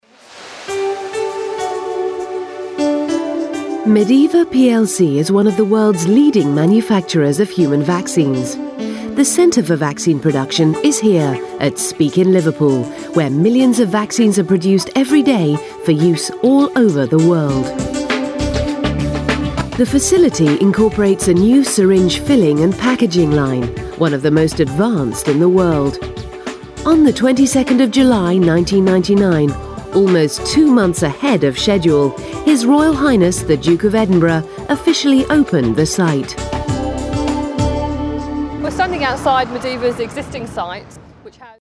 Warm, Confident, Persuasive British-English Female Voice Over
Corporate Voice Over Samples
FORMAL – Medeva PLC video presentation